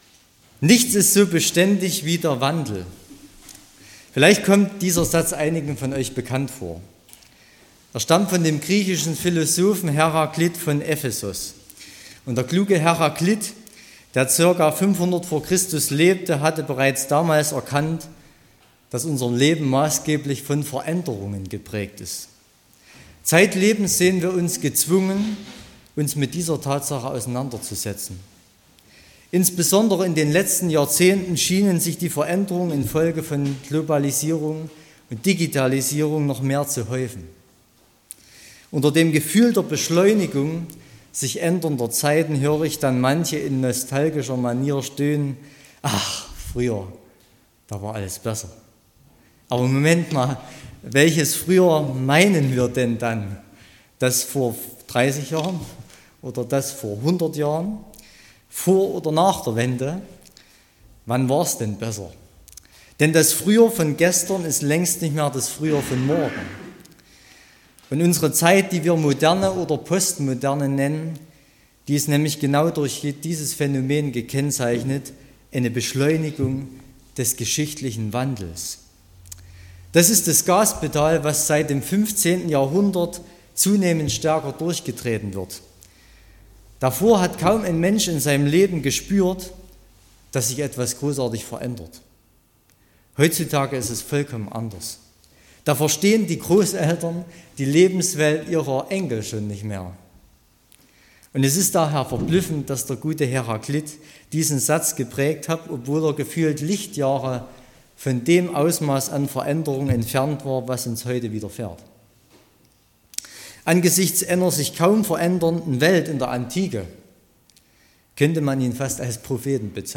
31.12.2024 – Gottesdienst
Predigt und Aufzeichnungen